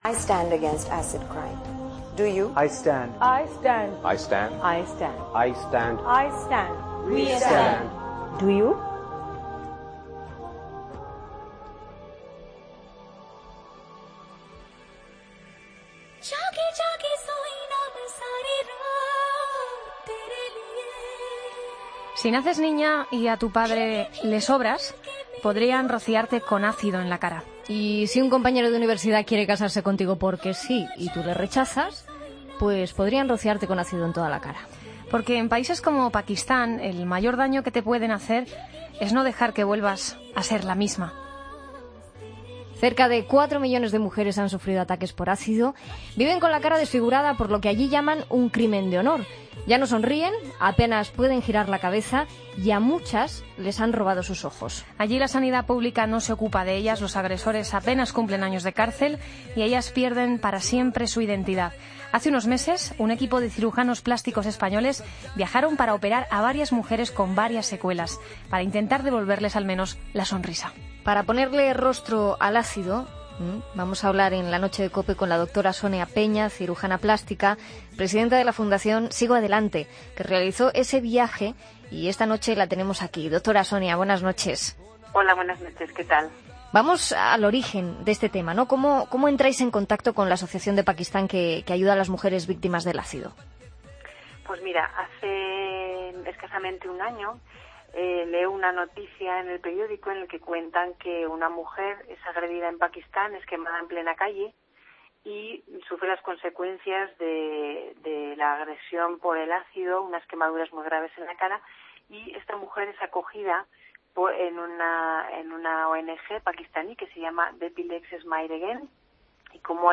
La Fundación Sigo Adelante realizó un viaje con varios cirujanos, para operar a las mujeres desfiguradas, este es el testimonio de una de las cirujanas.